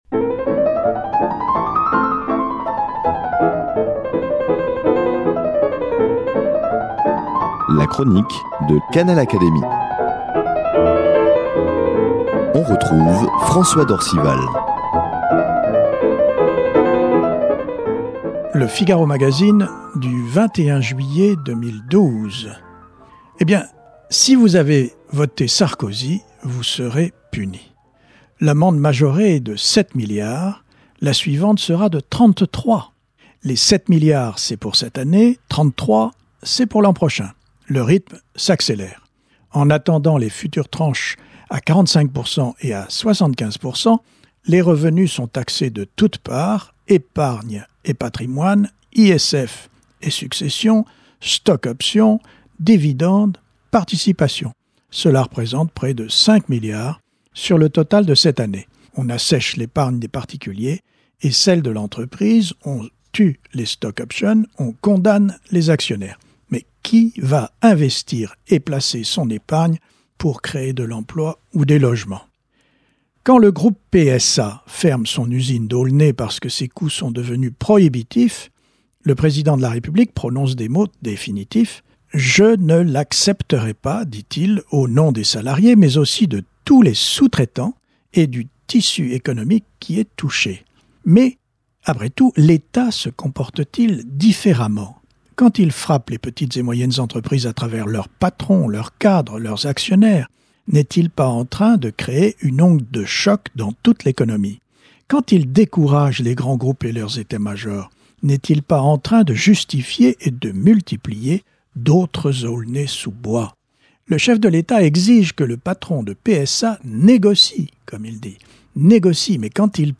Plus que jamais d’actualité... la chronique de François d’Orcival
Elle est reprise ici par son auteur, avec l’aimable autorisation de l’hebdomadaire.